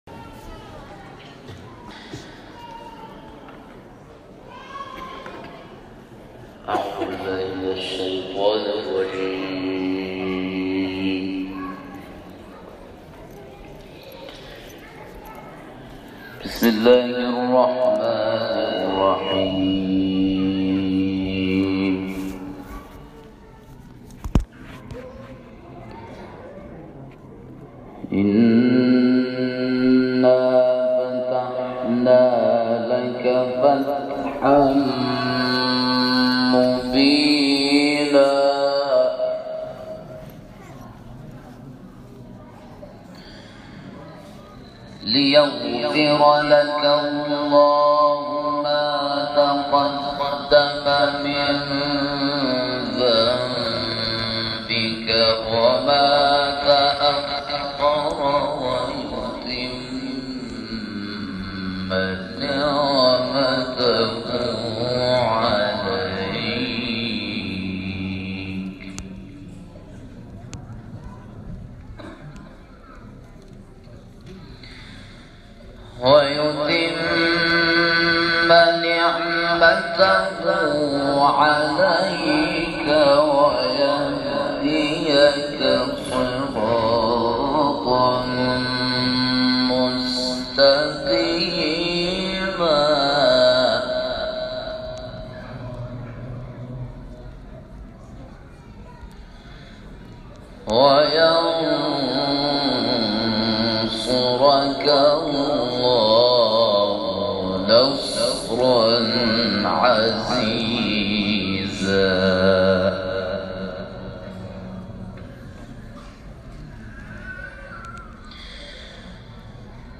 صوت/ تلاوت کاروان انقلاب در عنبرآباد و جیرفت
گروه چندرسانه‌ای ــ کاروان قرآنی انقلاب در پانزدهمین روز از سفر 22 روزه خود در دبیرستان دخترانه شبانه‌روزی عفاف عنبرآباد، مصلی شهرستان عنبرآباد و مسجدالرسول(ص) جیرفت استان کرمان محافل انس با قرآن کریم برگزار کرد.